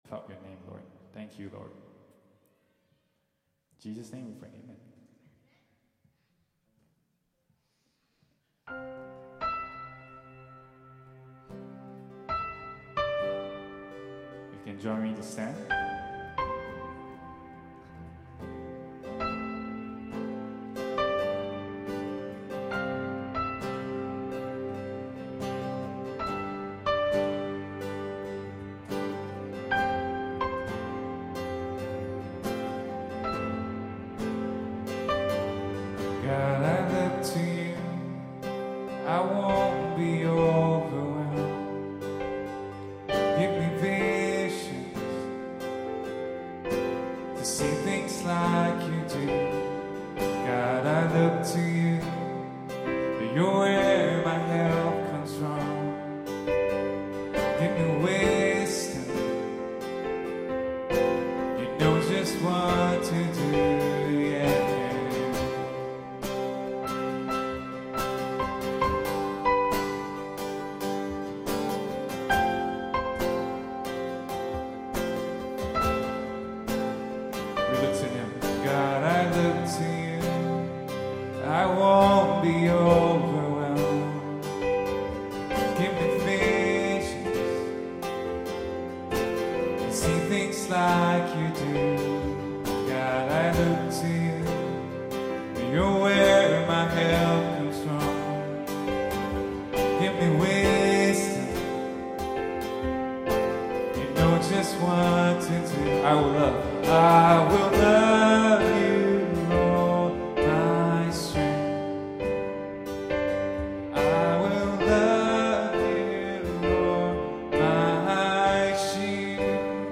Worship April 8, 2018